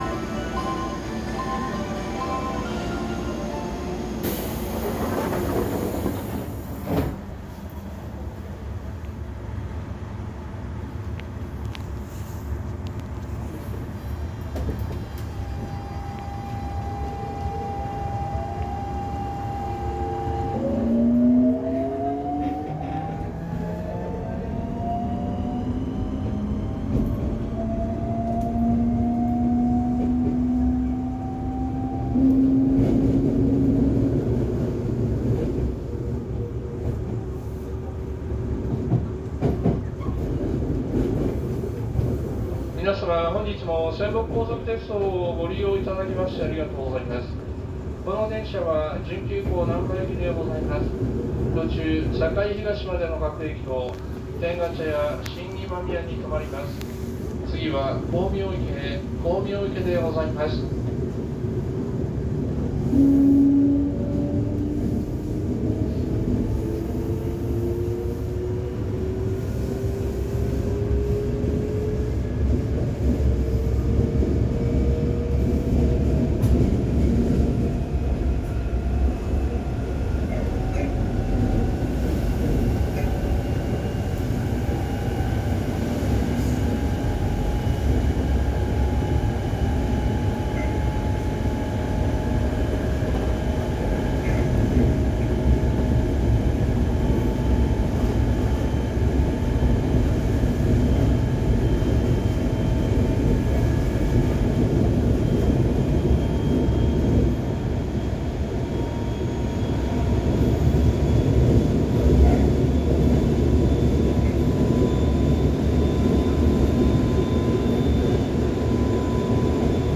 〜車両の音〜
・5000系走行音
【泉北高速線】和泉中央→光明池
日立GTOの後期型で非常に人気の高いモーター音ですが、徐々に更新が進められており少数派になりつつあります。